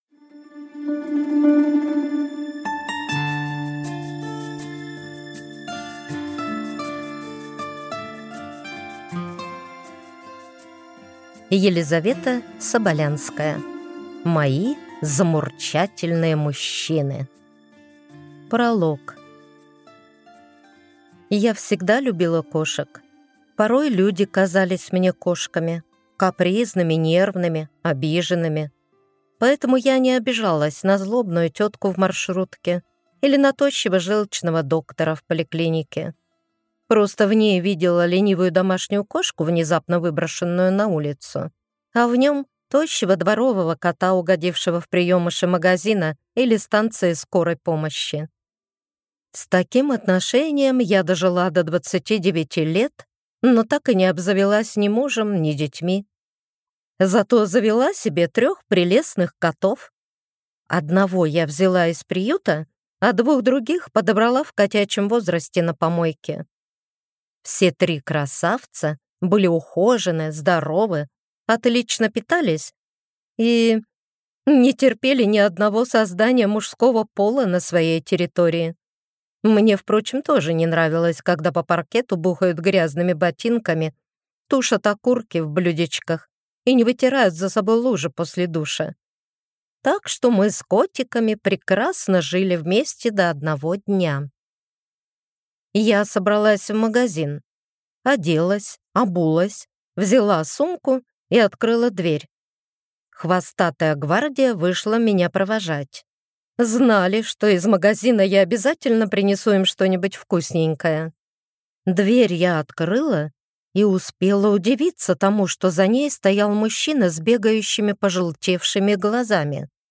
Аудиокнига Мои замурчательные мужчины | Библиотека аудиокниг